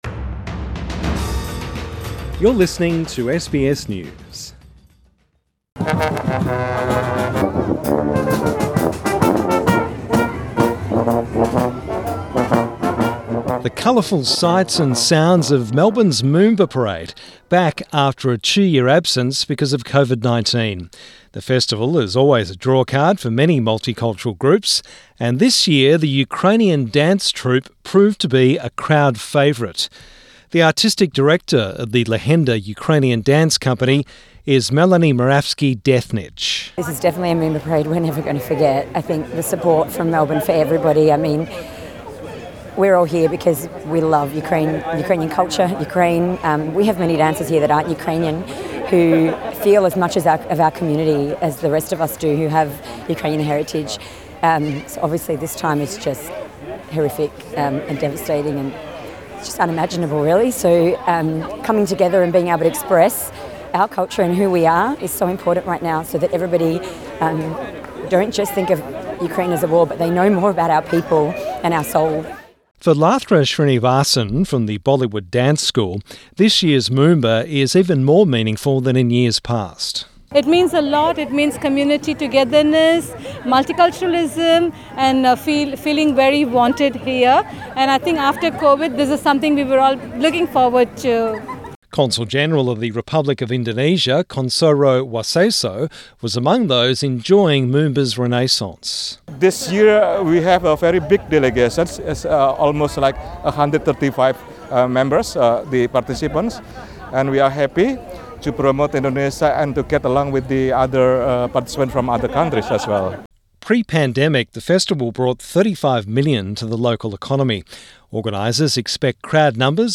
The colourful Moomba parade in Melbourne saw the return of dancers, all manner street performers and the cheering crowds.